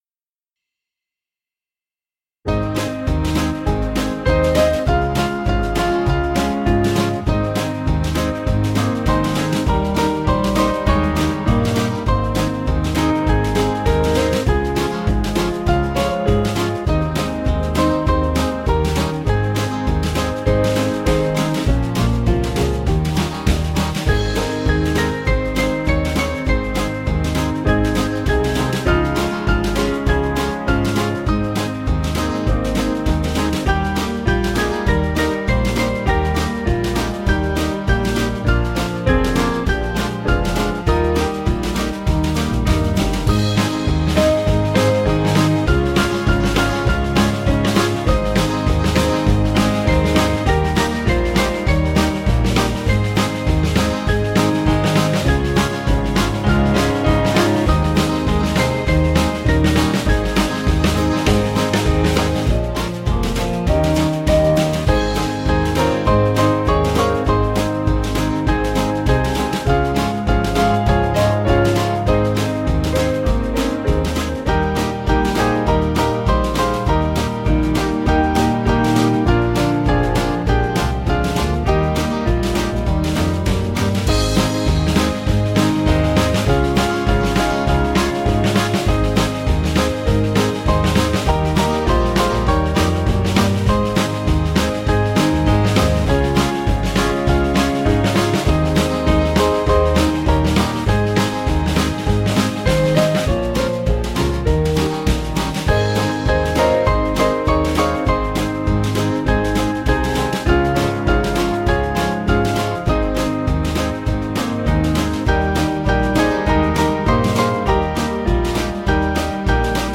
Small Band
(CM)   3/Ab